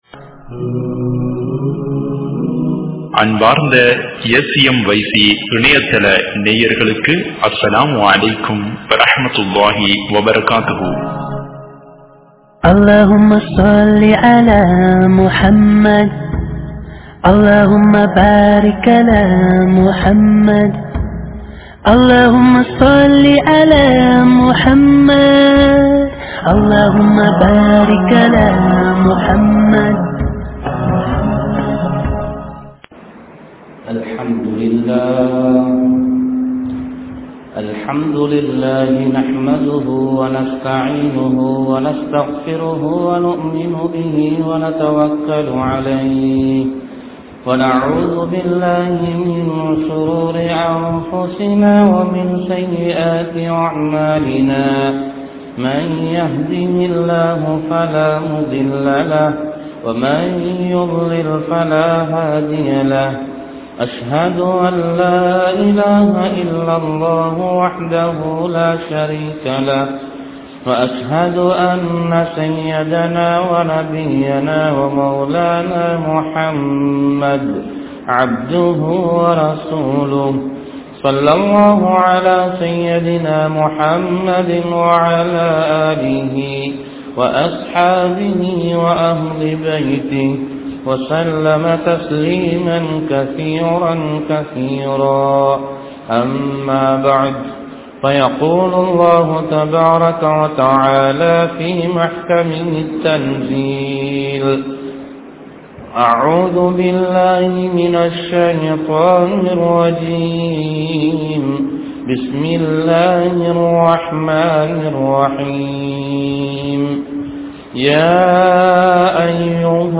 Nikkah Ean Avasiyam? (நிக்காஹ் ஏன் அவசியம்?) | Audio Bayans | All Ceylon Muslim Youth Community | Addalaichenai
Masjithur Ravaha